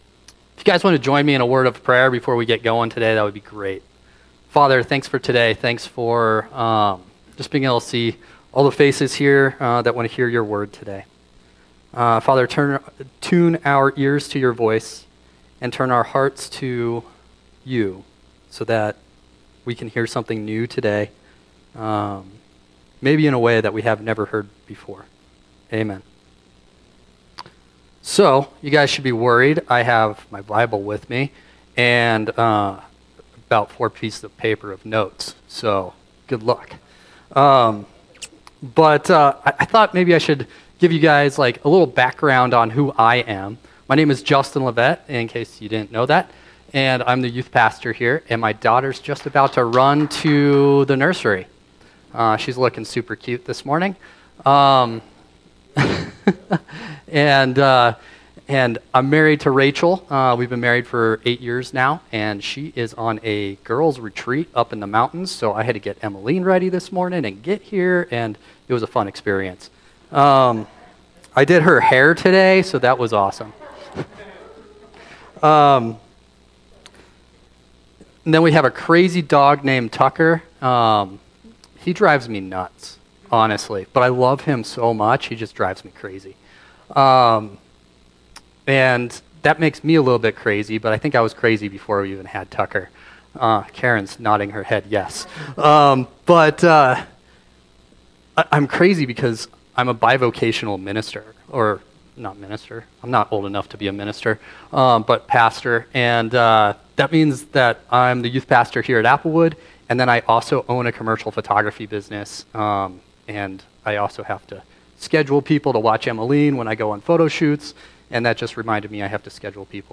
sermon - Applewood Community Church - Page 7